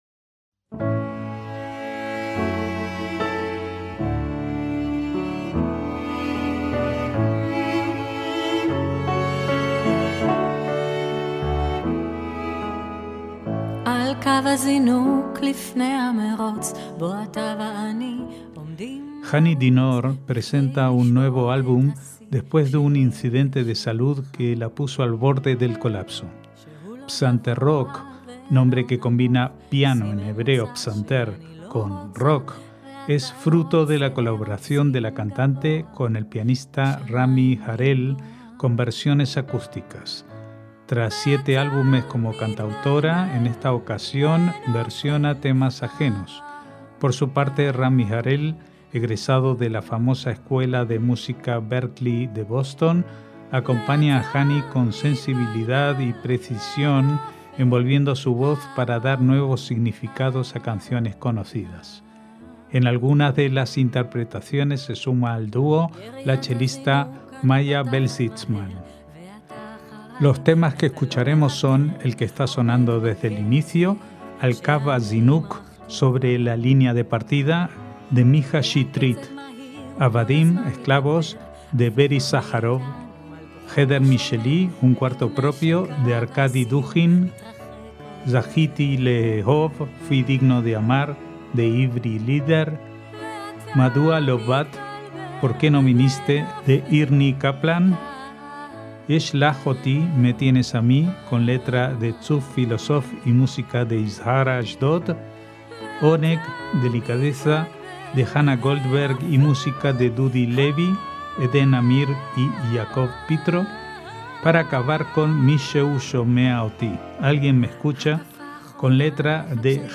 MÚSICA ISRAELÍ
con versiones acústicas.